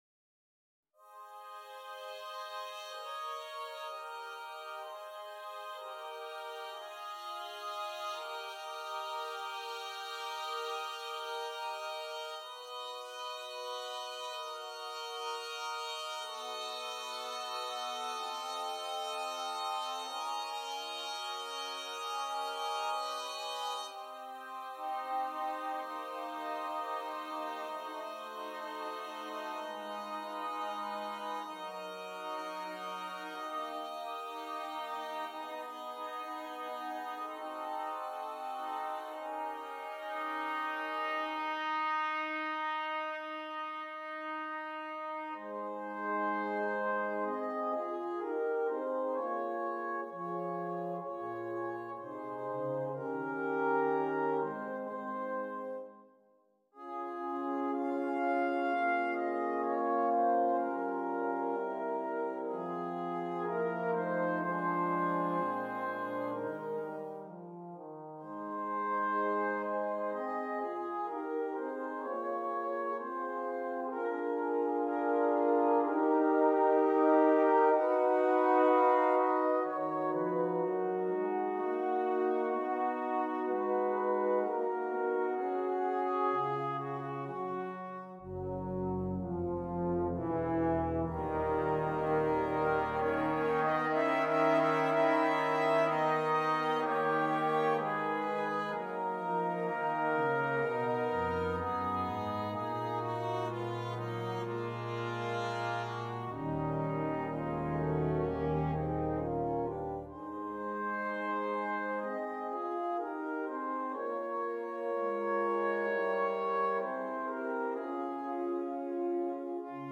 Besetzung: Cornet Solo & Brass Band